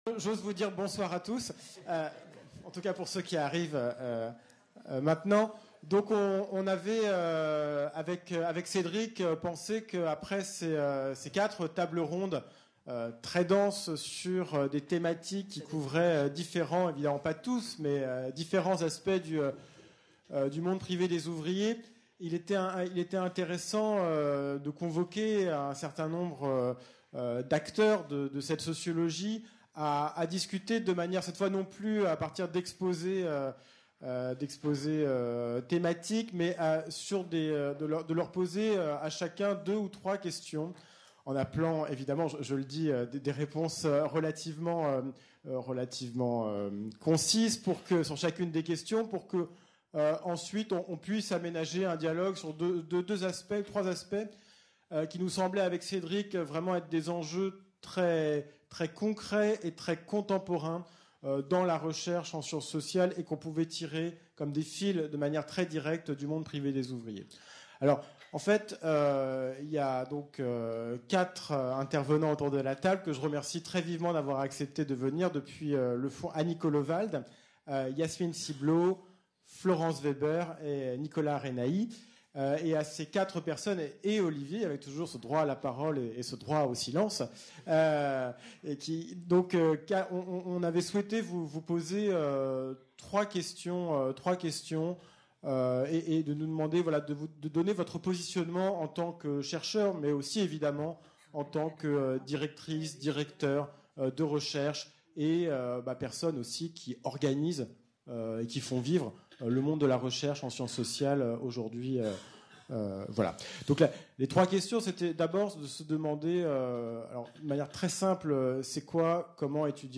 Echanges